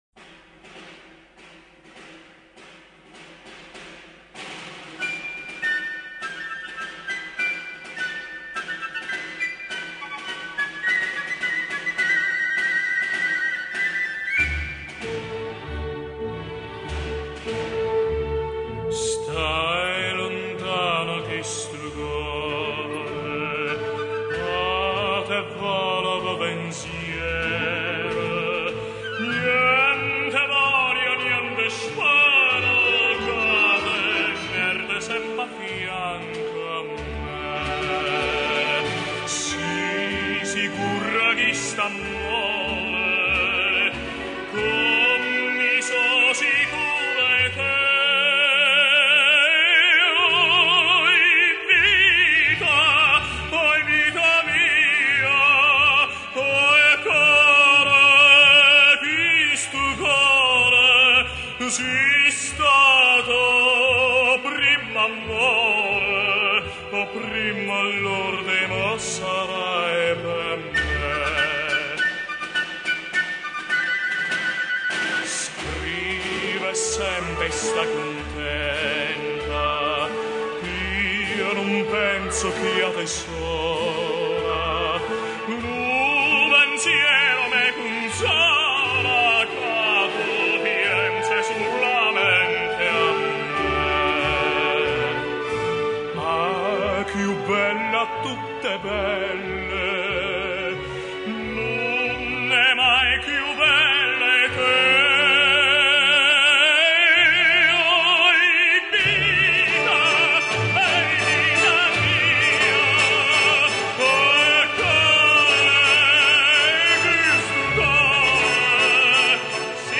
Italian songs.